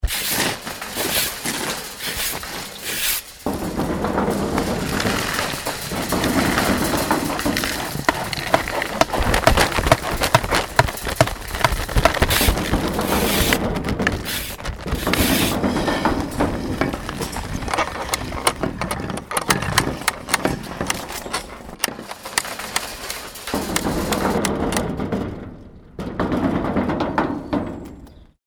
Aus vielen einzelnen Geräuschen haben die Jugendlichen der Our Voice-Redaktion rhythmische Collagen geschnitten.
Auf der Jagd nach Geräuschen pirschten sich die Jugendlichen mit ihren Aufnahmegeräten in den nahen Wald und zeigten sich überaus kreativ, was die selbständige Produktion von Geräuschen angeht.
Die einen schlagen verschiedene Stöcke gegen Bäume und bekommen jeweils deutlich unterscheidbare Schlaggeräusche. Andere erzeugen eine Vielzahl an Schritt-, Schlurf-, oder Stampfgeräusche, je nach Untergrund und Mikrofonposition variieren die Geräusche erheblich.
Ein Auto fährt vorbei, der Bach plätschert - aufnehmen und wahrnehmen, was uns im Alltag kaum auffällt. Und dann selbst aktiv werden: ein geworfener Stein prallt auf ein Straßenschild liefert ein überraschendes Dong, der über den Steinboden gezogene Sessel klingt wie ein brüllender Löwe.